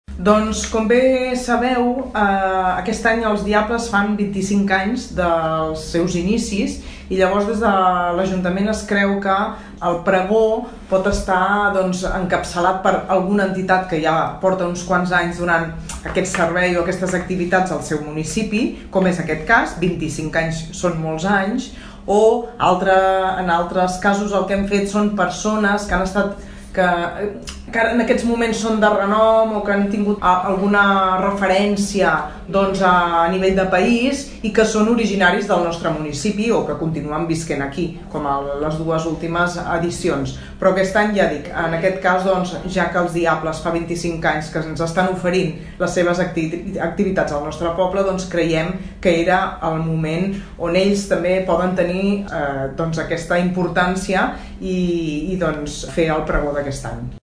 D’aquesta manera, l’Ajuntament de Tordera ha escollit els Diables de Tordera, com a responsables del pregó d’enguany, gràcies a la commemoració dels seus inicis. Ho explica Sílvia Català, regidora de Festes de l’Ajuntament de Tordera.